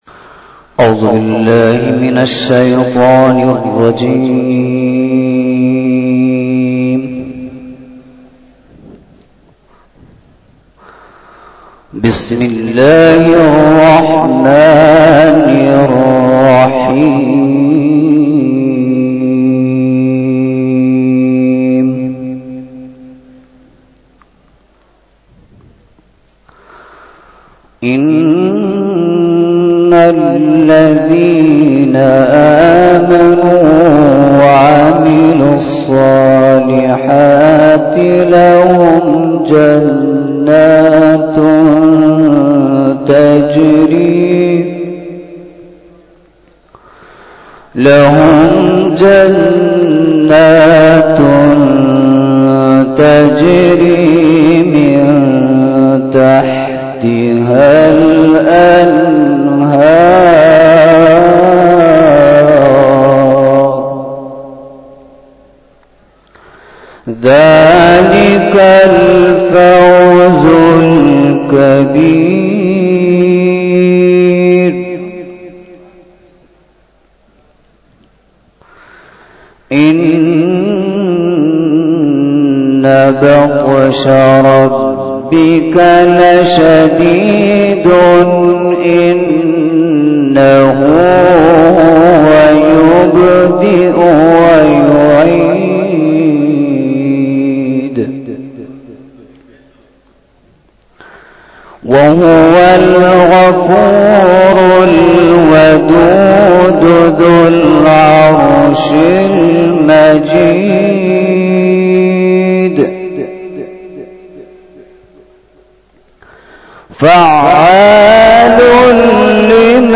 Taqreeb e Khatm E Bukhari 15 January 2023 ( 3 Rajab 1445 HJ)
Bayan